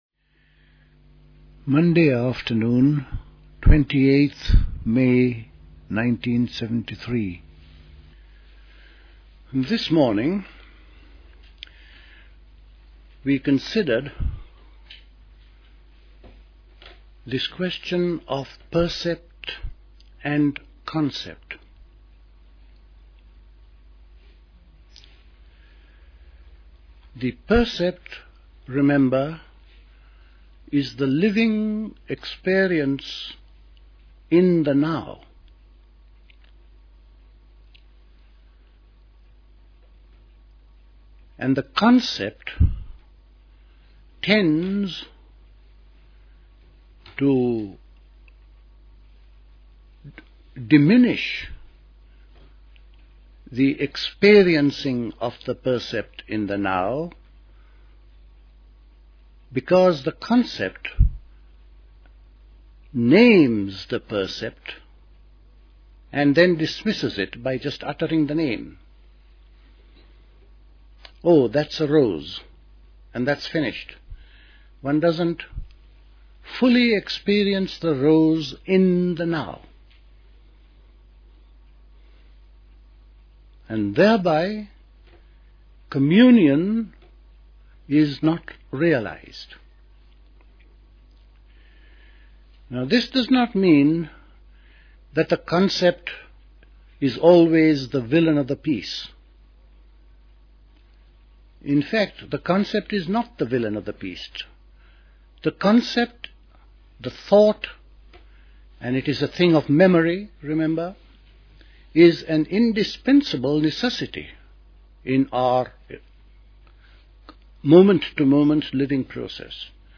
Recorded at the 1973 Catherington House Summer School. Follows on from the morning talk, Concept: Percept: Inner Sensitivity.